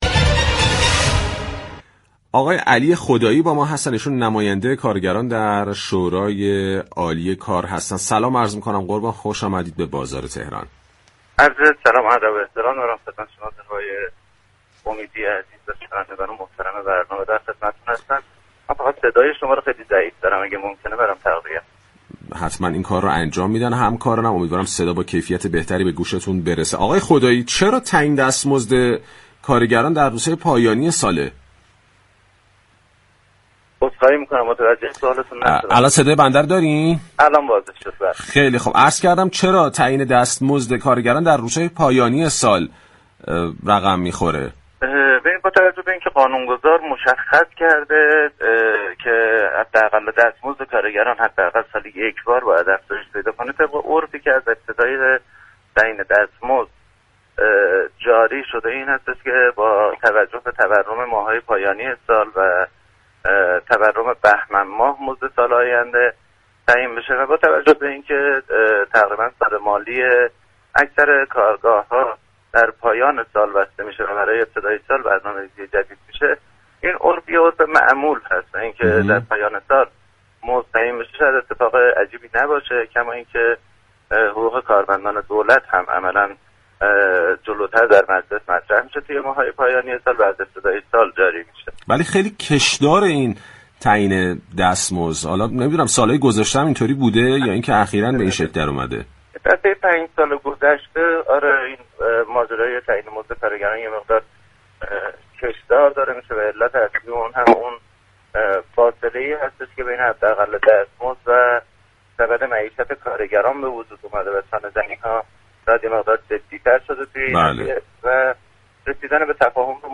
در گفتگو با برنامه بازار تهران